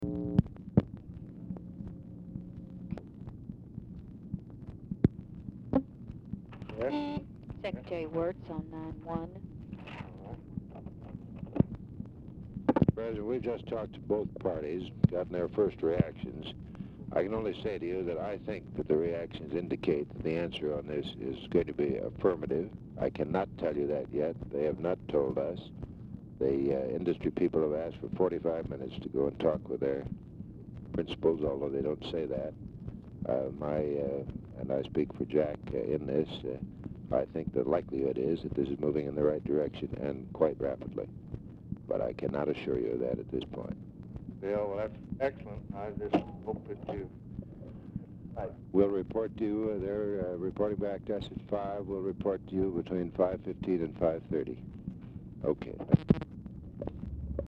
Telephone conversation # 8833, sound recording, LBJ and WILLARD WIRTZ, 9/3/1965, 4:25PM
Format Dictation belt